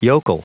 Prononciation du mot yokel en anglais (fichier audio)
Prononciation du mot : yokel